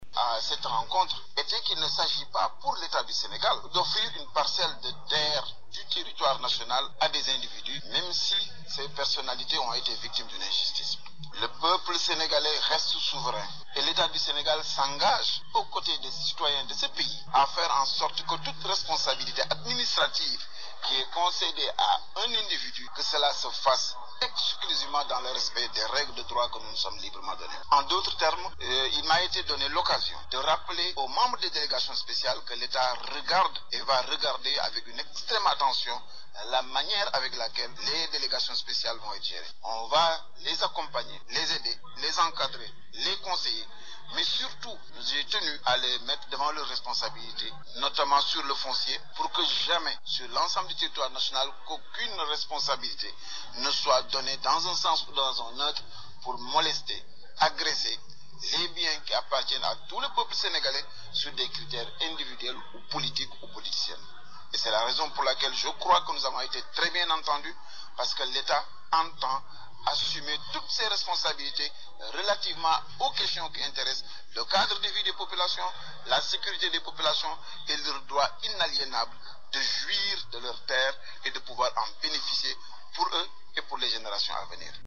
» Le ministre de l’aménagement du territoire et des collectivités locales, Cheikh Bamba Dièye, sur les ondes de la Rfm, invite les nouveaux présidents de délégation spéciale à faire une gestion transparence des finances et du foncier. M. Dièye soutient l’Etat garde un œil de lynx sur ces derniers.